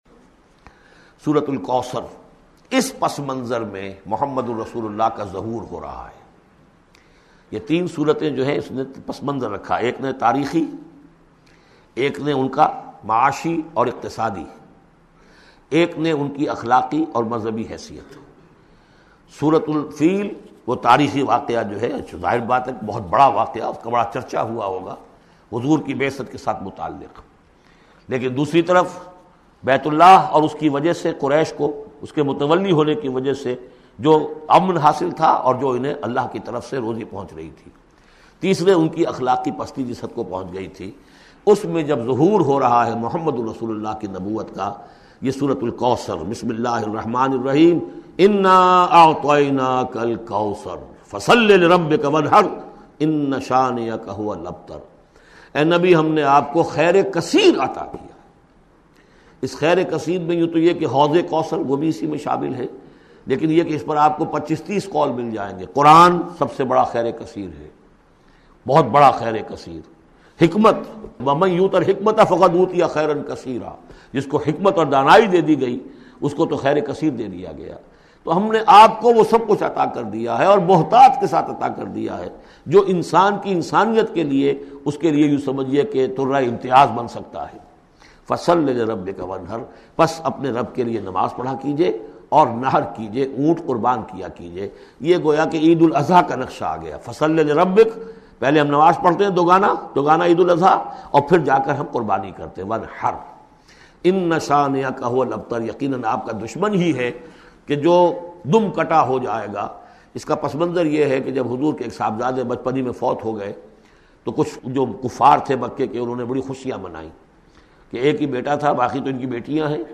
Surah Kausar Audio Tafseer by Dr Israr Ahmed
Surah Kausar is 108 chapter of Holy Quran. Listen online mp3 audio tafseer of Surah Kausar in the voice of Dr Israr Ahmed.